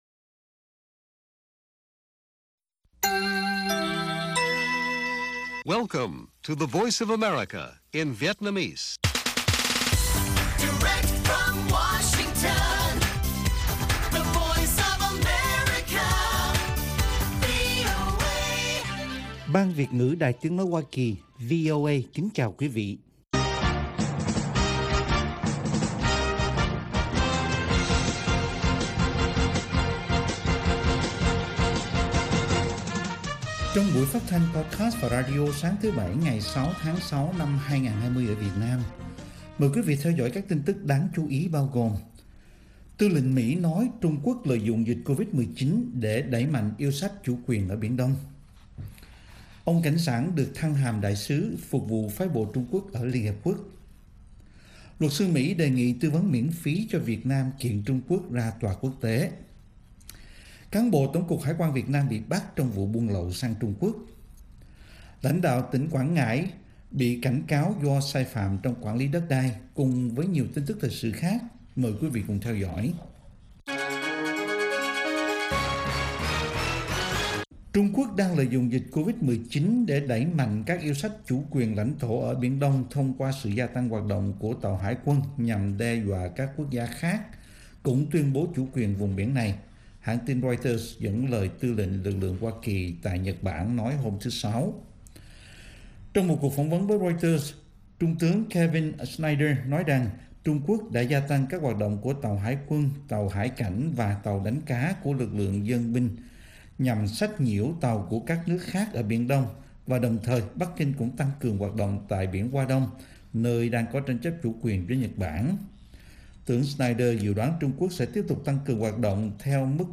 Bản tin VOA ngày 6/6/2020